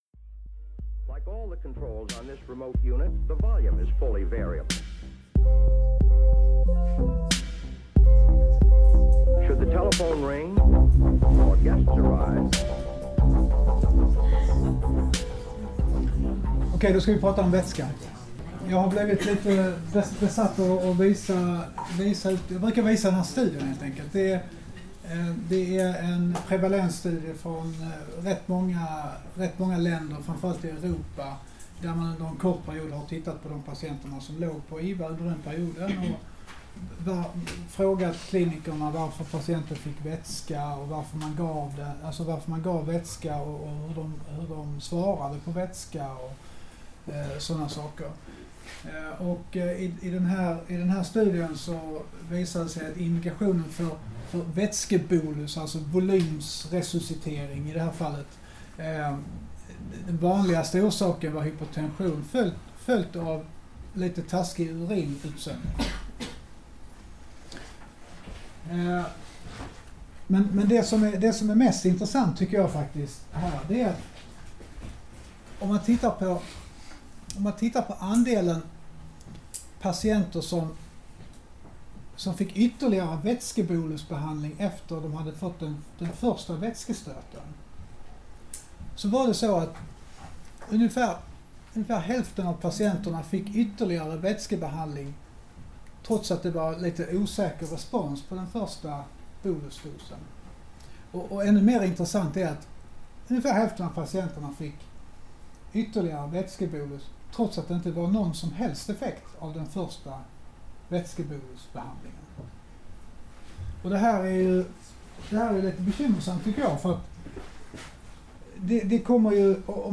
Inspelningen gjordes på dialyskursen, som hölls på Karolinska i Solna våren 2019.